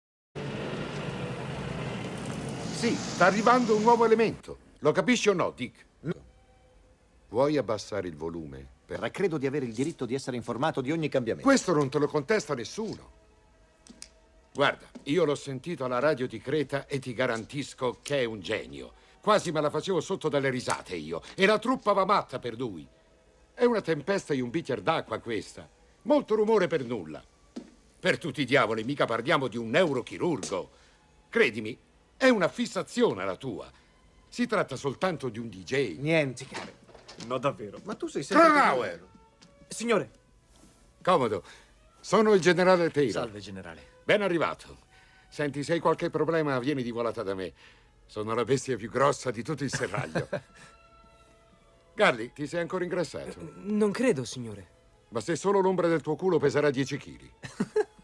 voce di Luigi Pistilli nel film "Good Morning, Vietnam", in cui doppia Noble Willingham.